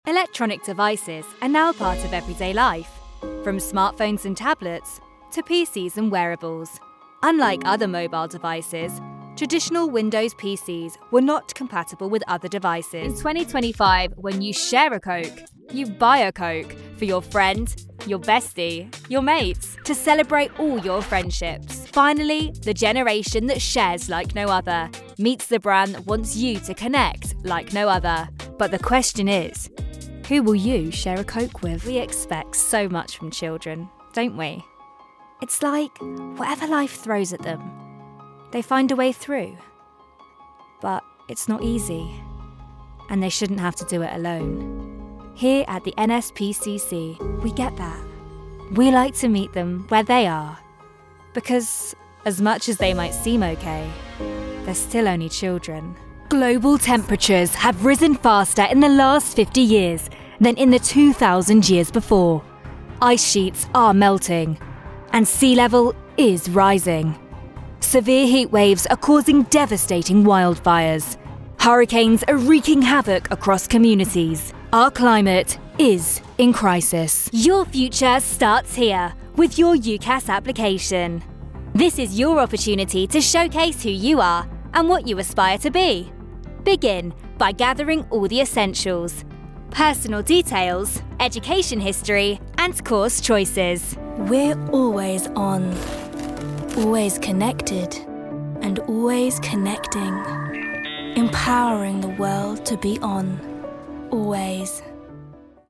Comercial, Joven, Cool, Versátil, Amable
Explicador